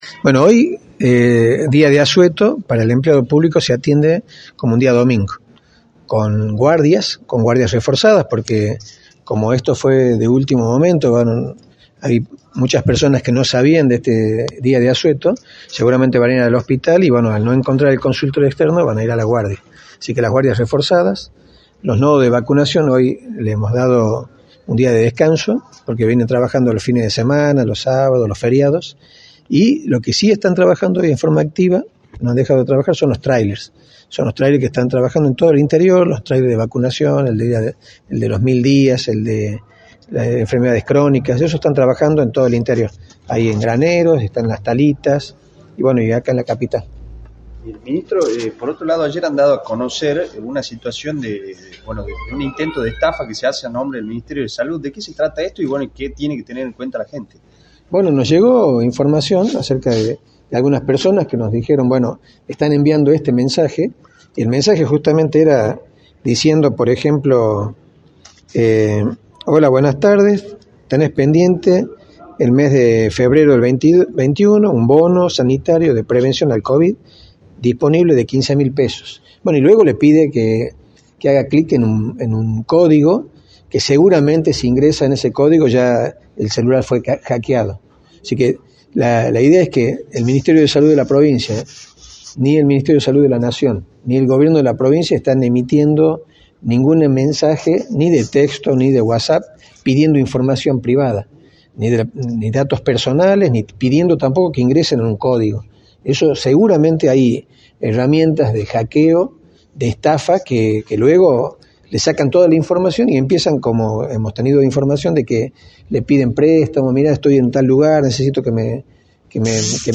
“No acepten ni ingresen en códigos de desconocidos, ni envíen sus datos personales, es muy peligroso porque pueden perder toda la información de sus celulares, incluso pueden perder dinero” remarcó el Ministro de Salud en entrevista para Radio del Plata Tucumán, por la 93.9.